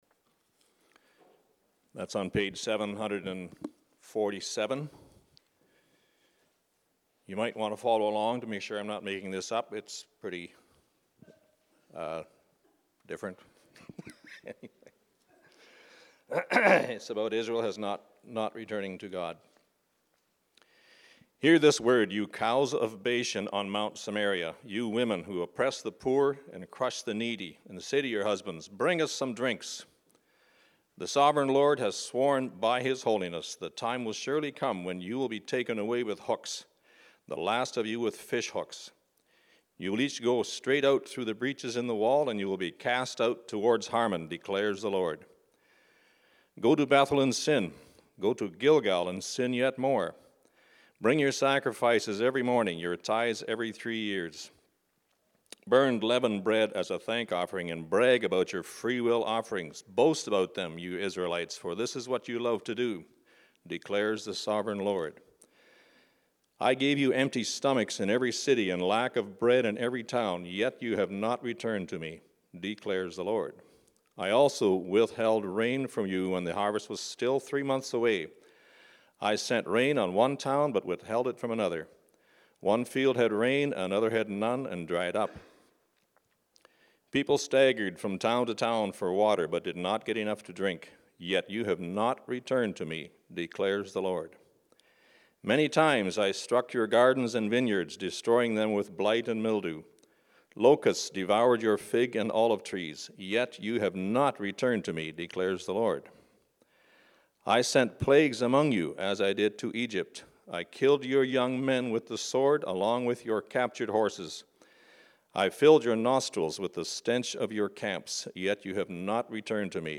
Sermon Messages - Lucknow Community Christian Reformed Church